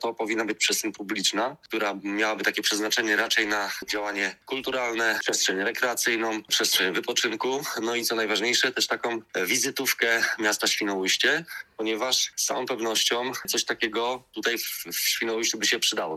Świnoujście rozważa jak można ożywić Basen Północny – podczas Nocy Muzeów odbyła się debata z udziałem ekspertów: urbanistów, architektów czy organizatorów festiwali.